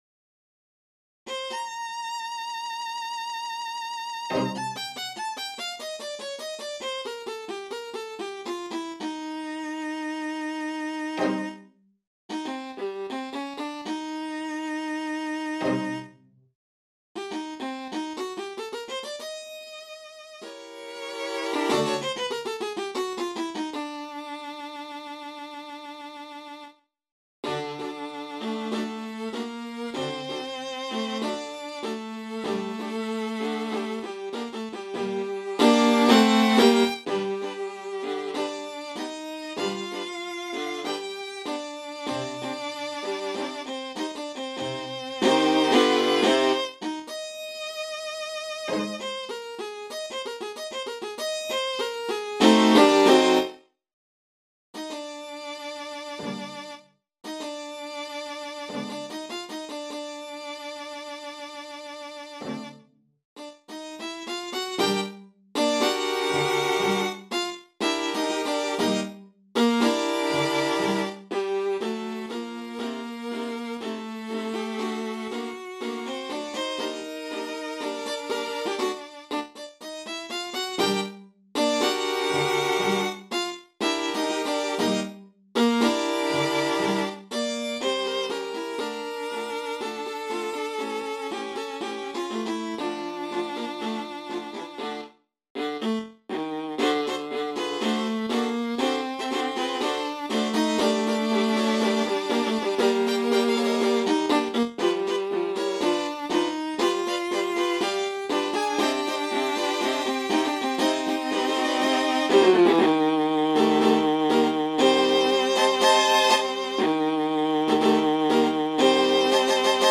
Kamermuziek: Acht altviolen
tango tzigane